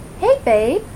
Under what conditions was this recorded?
Please note, I cannot eliminate all of the background noise, but this is an option for those of you with more sensitive ears.